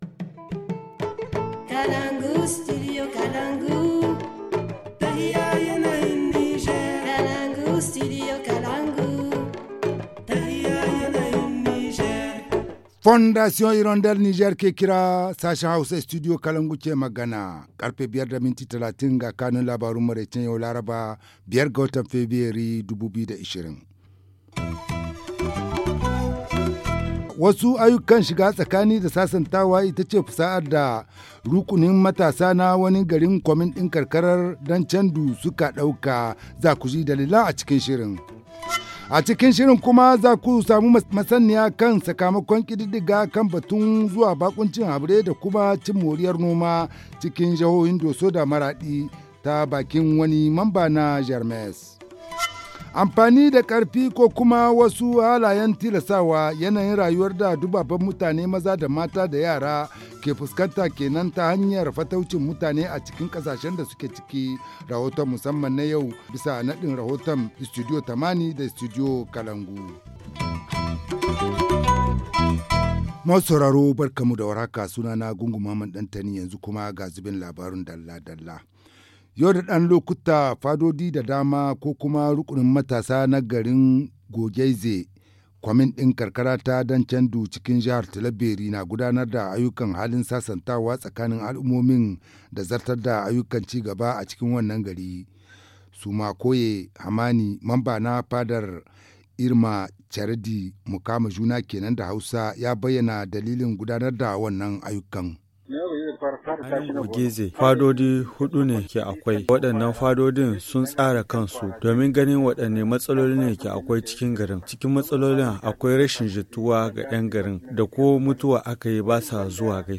Le journal du 05 février 2020 - Studio Kalangou - Au rythme du Niger